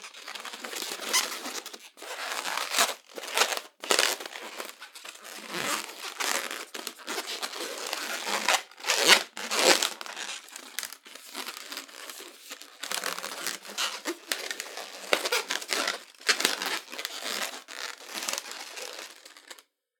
Twisting Balloon Animal(Poodle)
animal balloon balloon-animal clown OWI party rubber squeaky sound effect free sound royalty free Animals